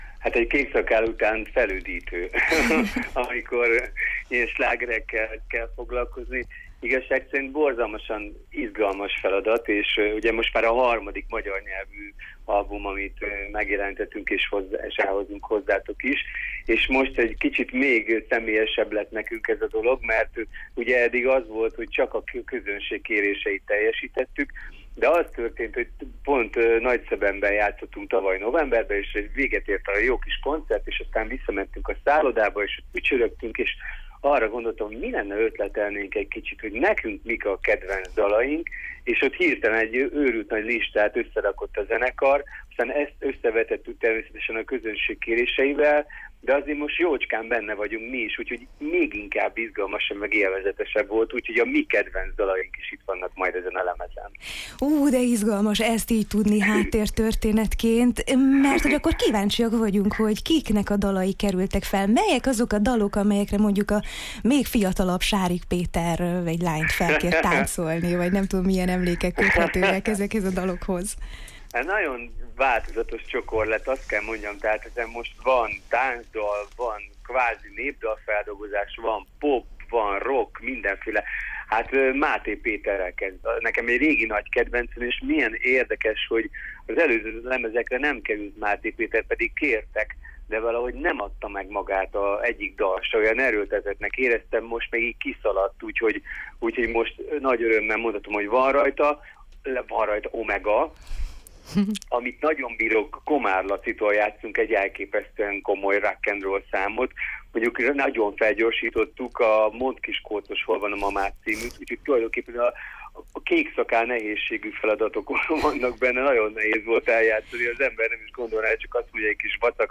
A turné utolsó állomása Nagyvárad, május 14-én. A részletekért ajánljuk a beszélgetést: